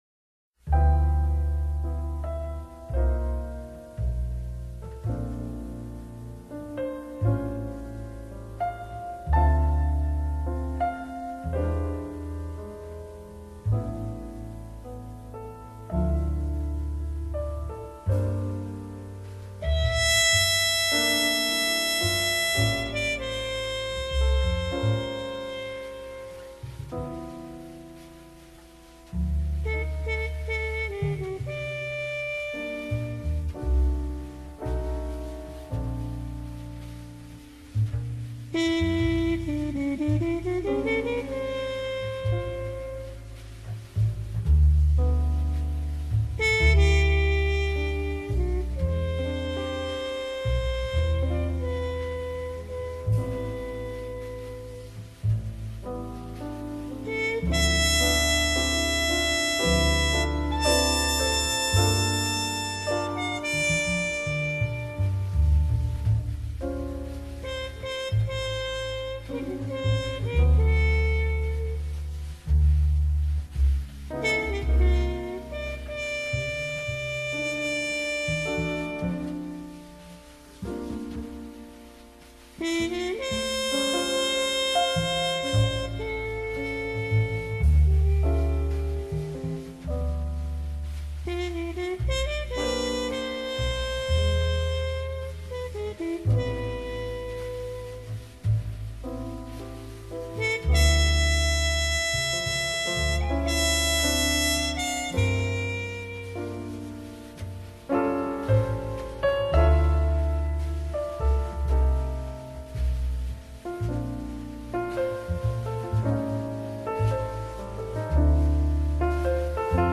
Época y 3 características del COOLJAZZ
Más tranquilo, experimentan creando formas musicales etéreas. Se modera el tiempo, el timbre y las dinámicas (fortes y pianos suaves).